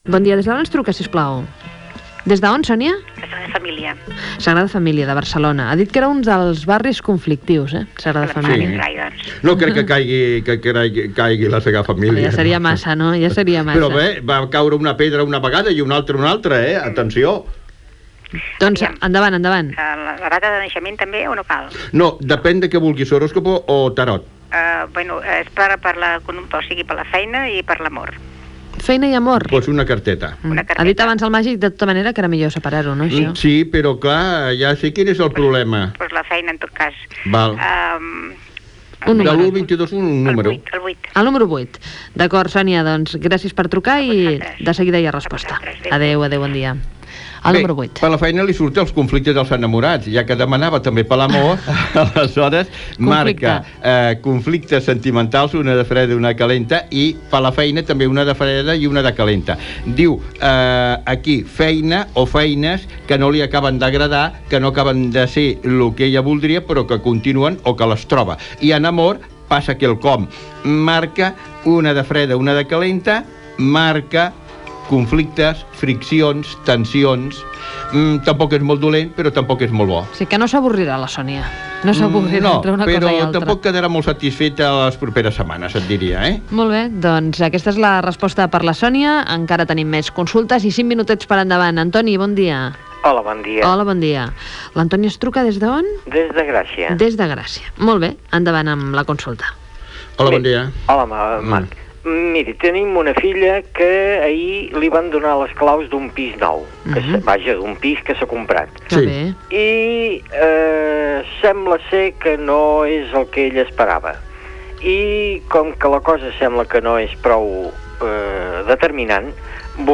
Indicatius de la ràdio i del programa.
Entrevista a Màrius Serra que ha publicat "Ablatanalba"
Info-entreteniment